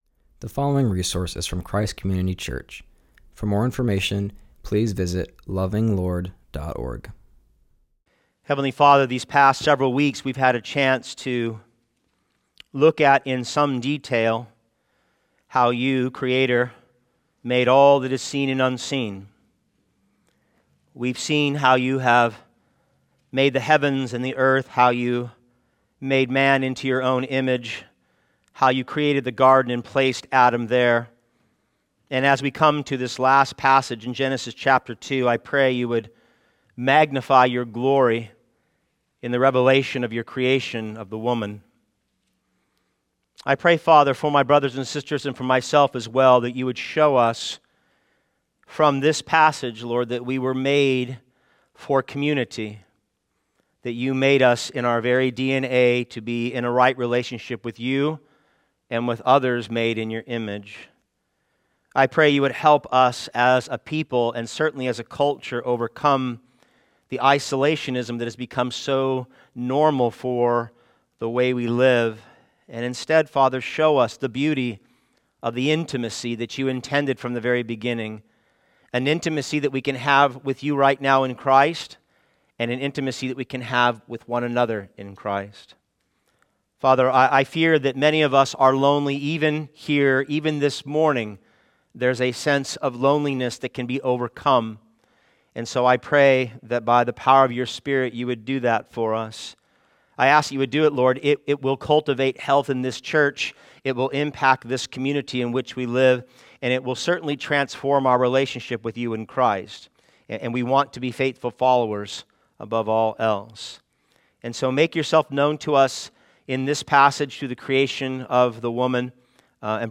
preaches from Genesis 2:18-25.